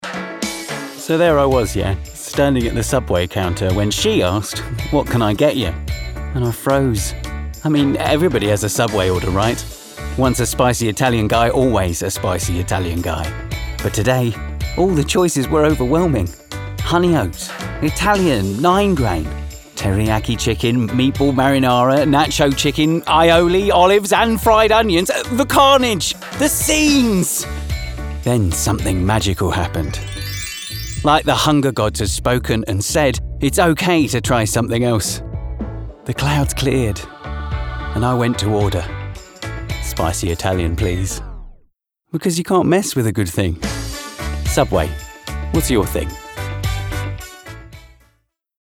Voice Reel
Subway Commercial - Playful, Wry, Cheeky